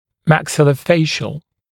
[mækˌsɪlə(u)’feɪʃl][мэкˌсило(у)’фэйшл]челюстно-лицевой